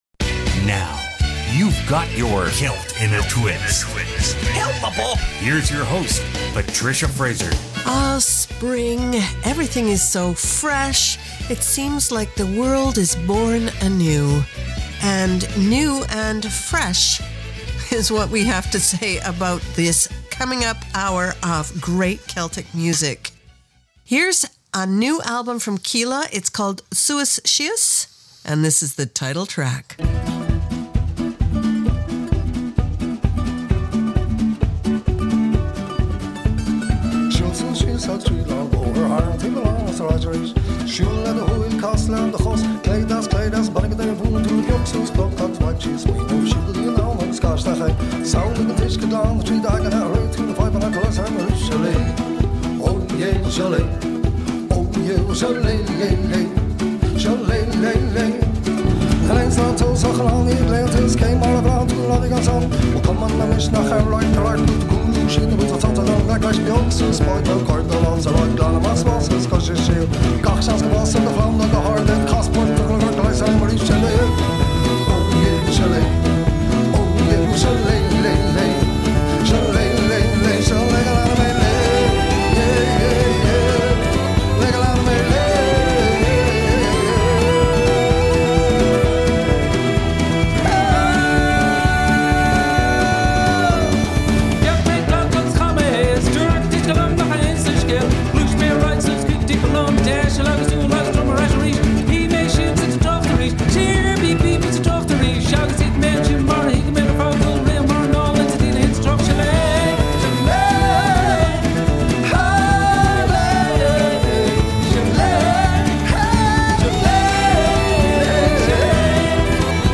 Canada's Contemporary Celtic Radio Hour
New Celtic fresh as Spring!